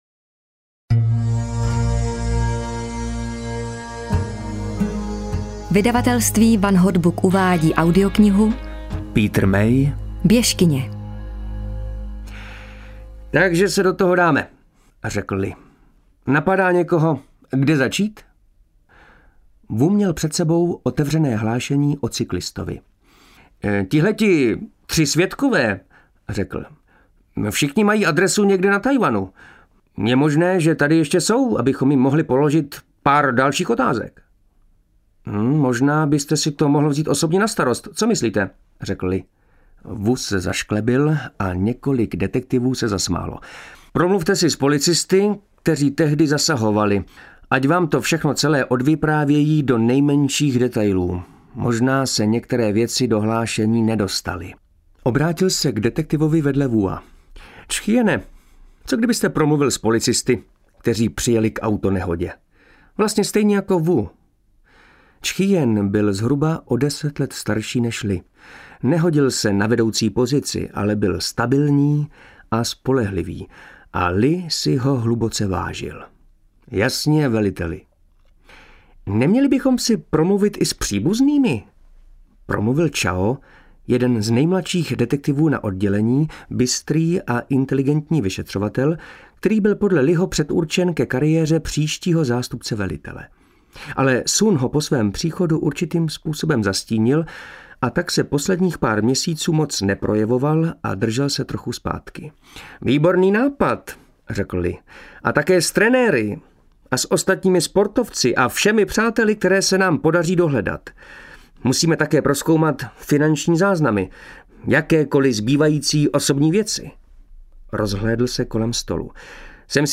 Běžkyně audiokniha
Ukázka z knihy
• InterpretMartin Myšička, Jana Plodková
bezkyne-audiokniha